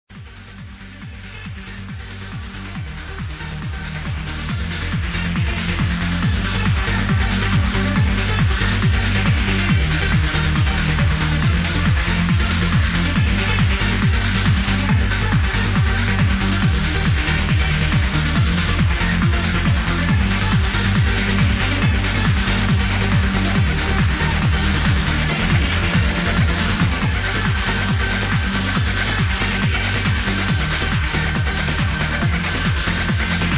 heard this in a older set